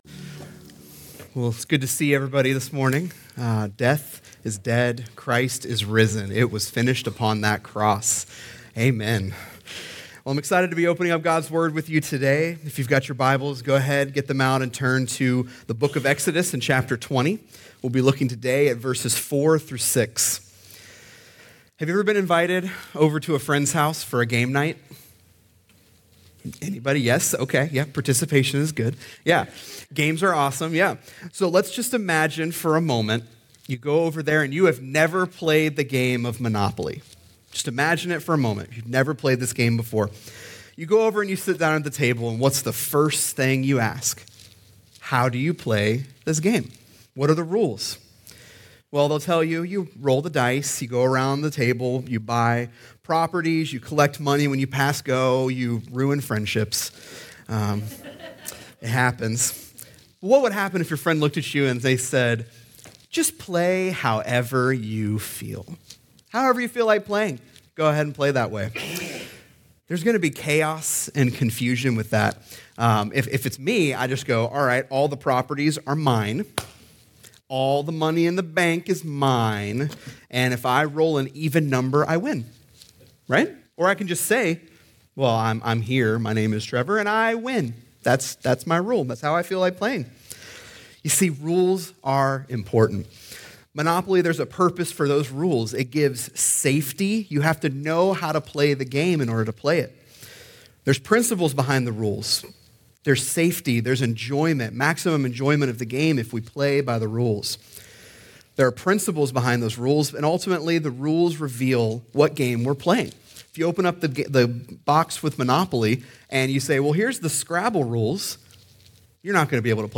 Check out this week's message as we look at the 2nd Commandment…